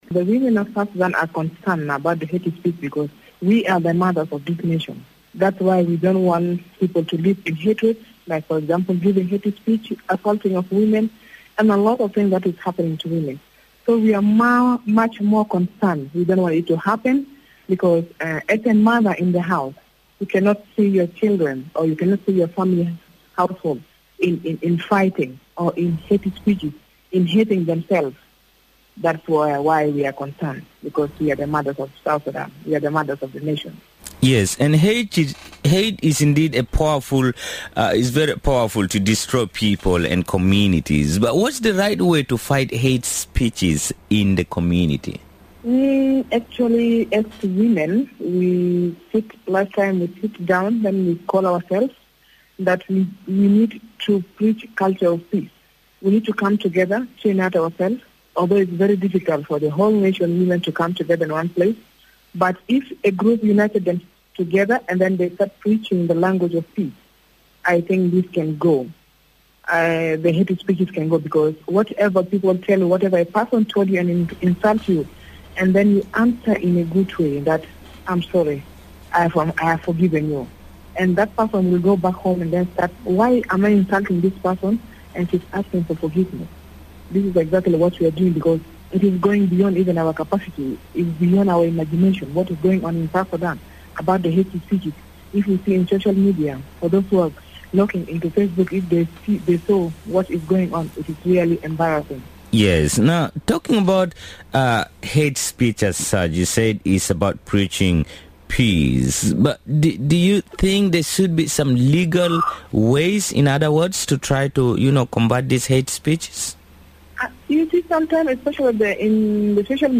Radio Miraya